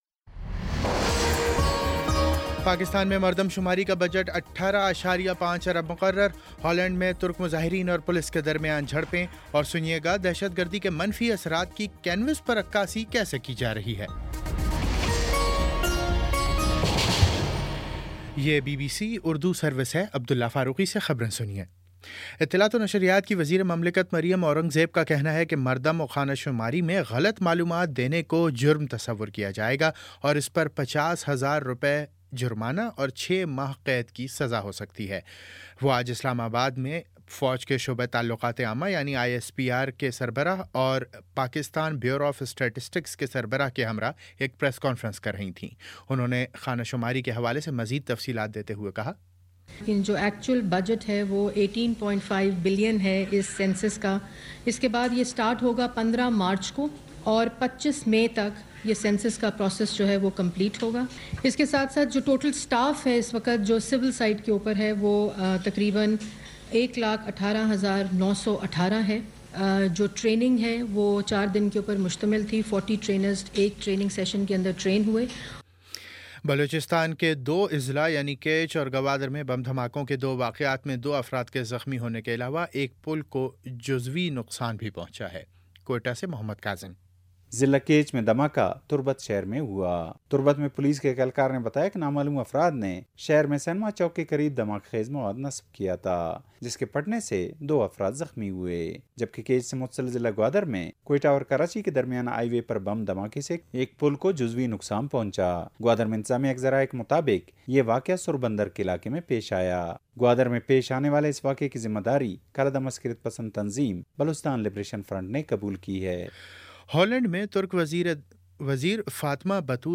مارچ 12 : شام چھ بجے کا نیوز بُلیٹن